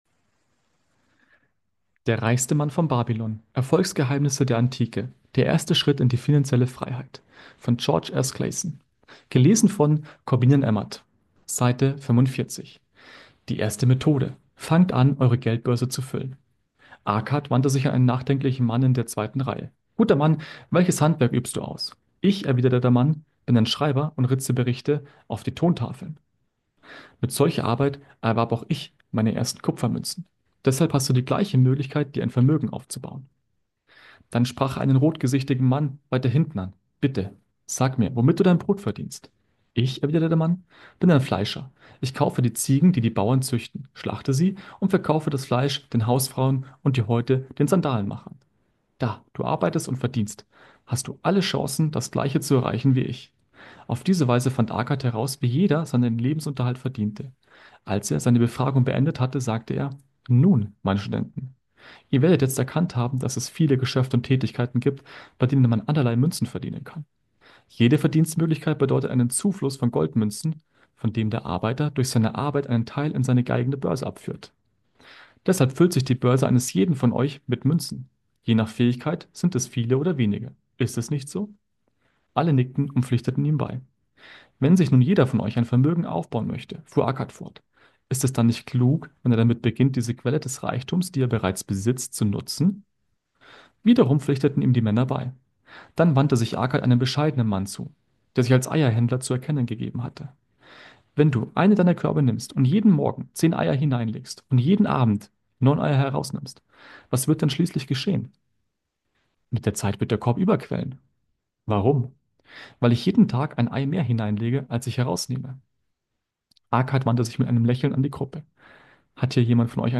Lesung aus dem Buch